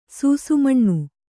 ♪ sūsu maṇṇu